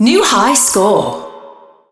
new_high_score.wav